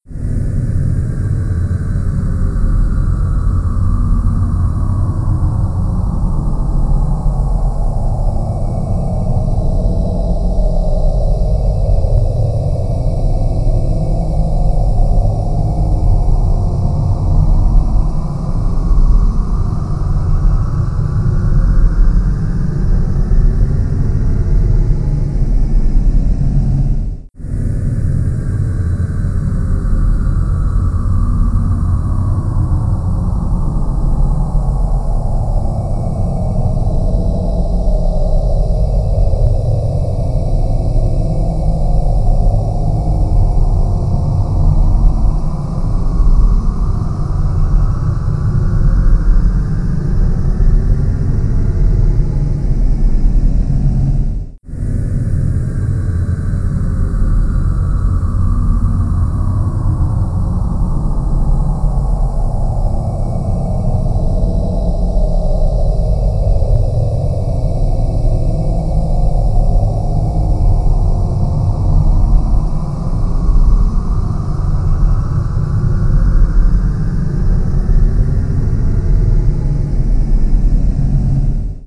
at 150% speed
Soundtrack